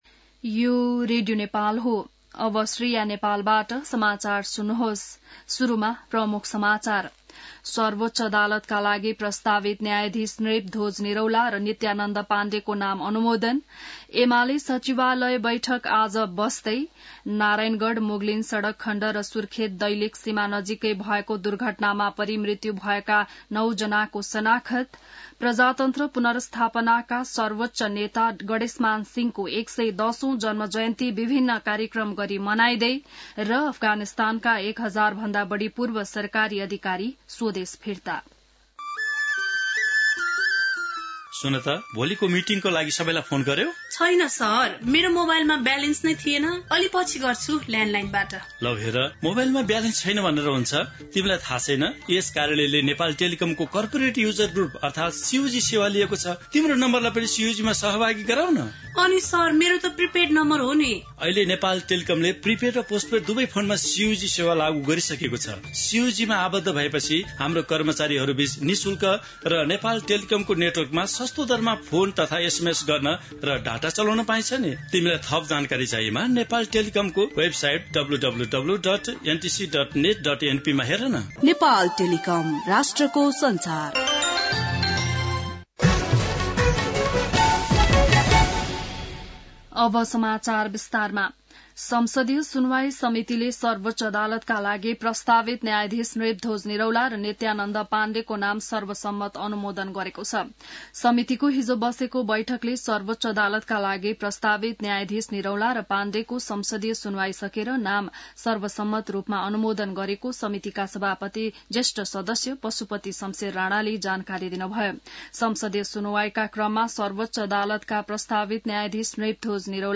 बिहान ७ बजेको नेपाली समाचार : २५ कार्तिक , २०८१